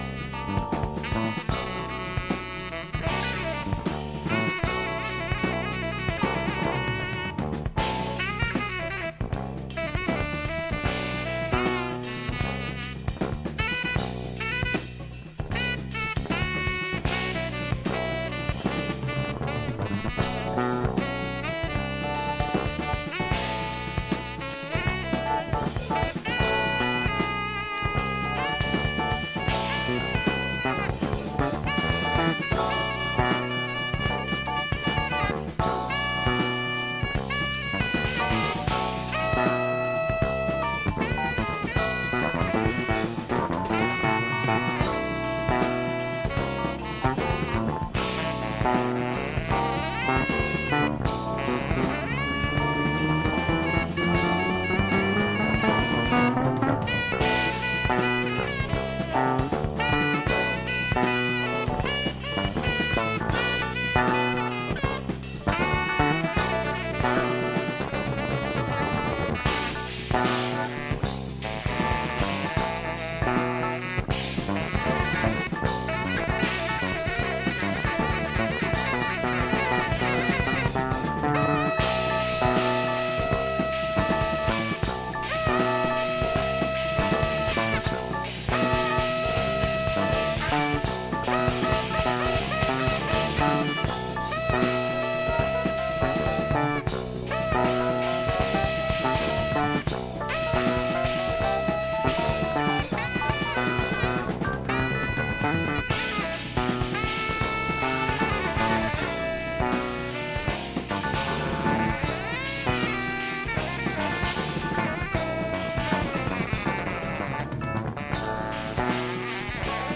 Rare Jazz-Funk album
bass